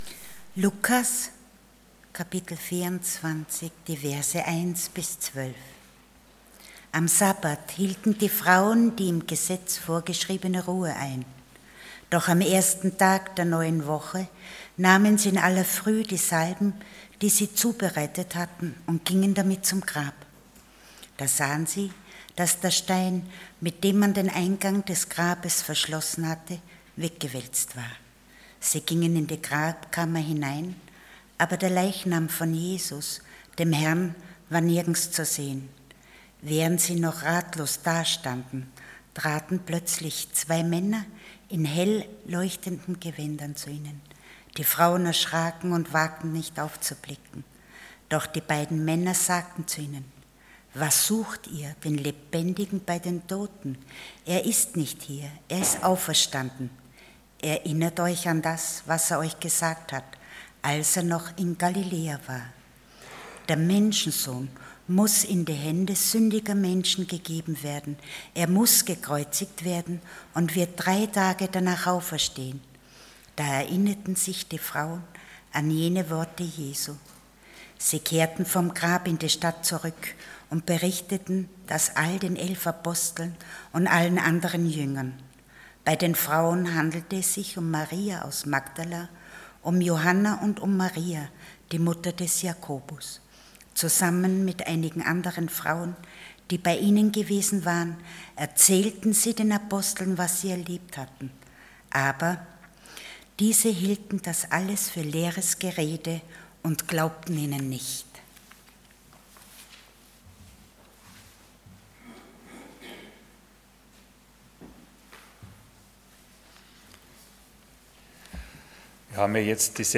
Passage: Luke 24:1-11 Dienstart: Sonntag Morgen